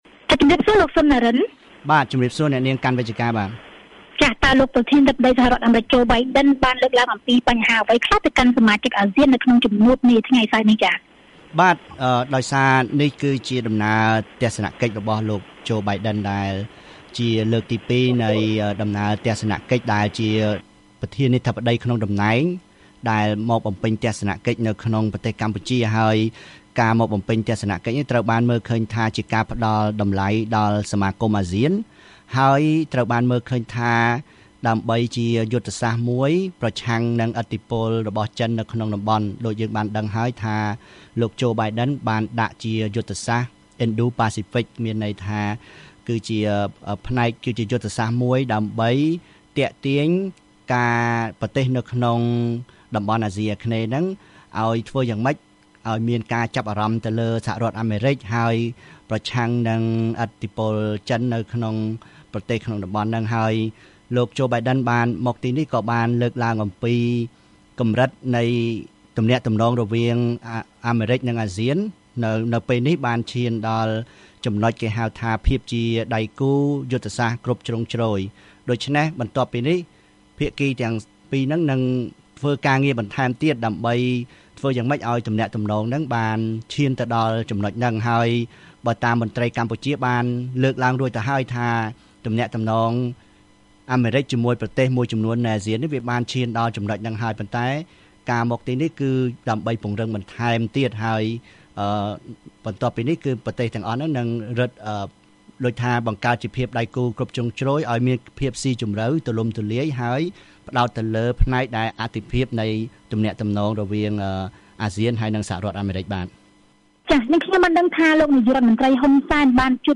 កិច្ចសន្ទនា VOA៖ សង្ខេបថ្ងៃទី២នៃកិច្ចប្រជុំអាស៊ានដែលកម្ពុជាធ្វើជាម្ចាស់ផ្ទះ